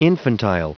Prononciation du mot infantile en anglais (fichier audio)
Prononciation du mot : infantile